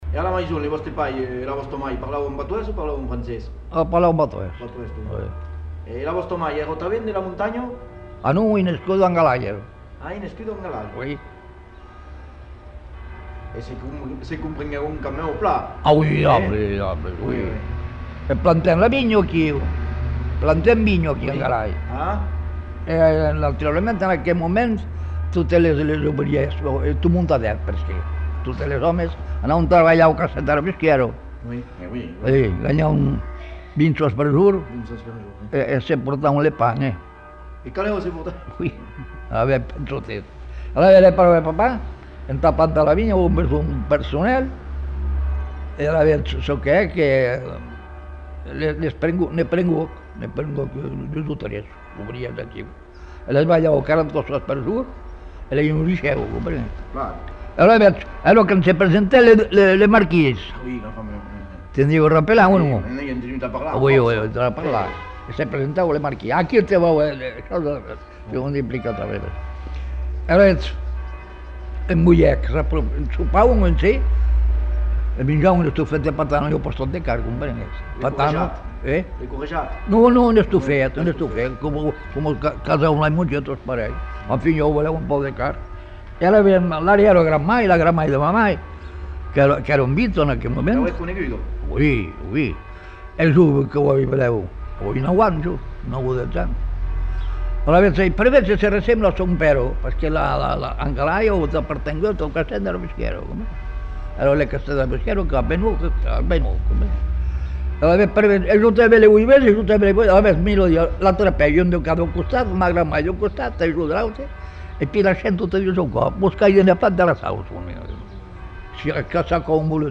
Lieu : Lombez
Genre : conte-légende-récit
Effectif : 1
Type de voix : voix d'homme
Production du son : parlé
Classification : récit anecdotique
Notes consultables : Bruits de fond.